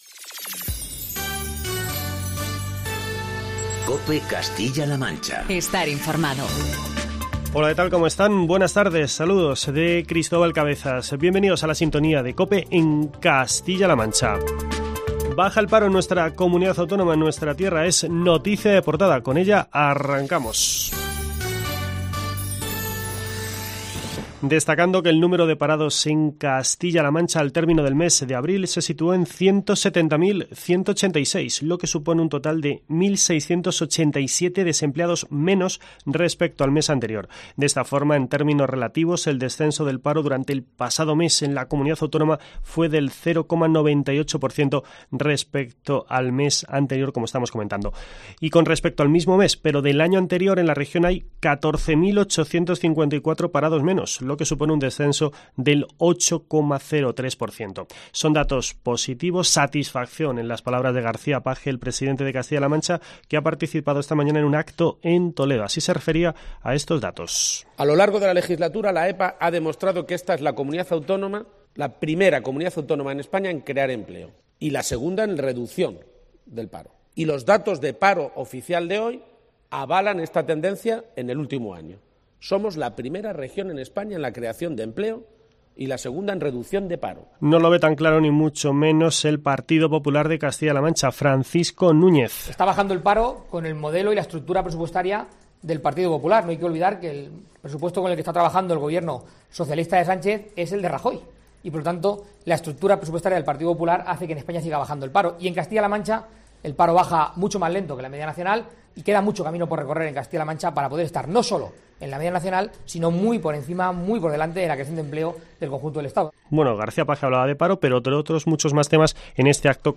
Así lo ha puesto de manifiesto durante un desayuno informativo organizado por el diario La Tribuna